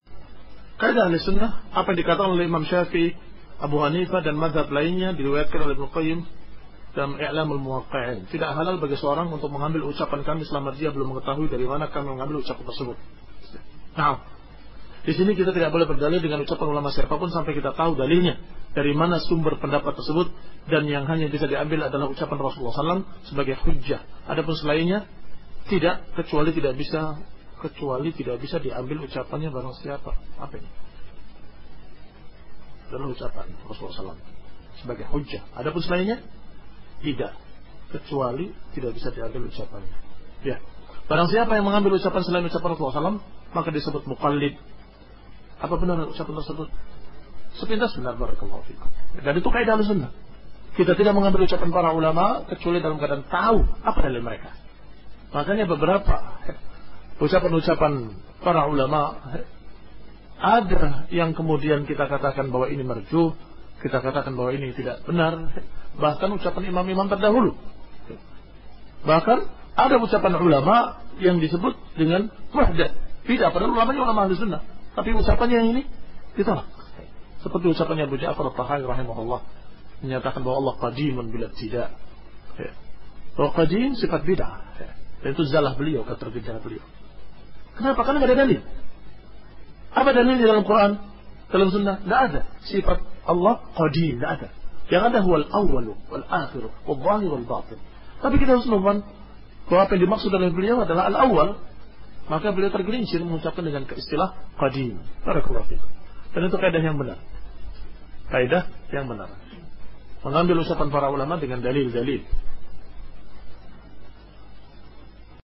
Sesi Tanya Jawab, Kajian Kitab Kun Salafiyan 'Alal Jaaddah // Sabtu, 04 Rabi'uts Tsani 1436H - 25/01/2015M // Masjid Al-Mujahidin Slipi, Jakbar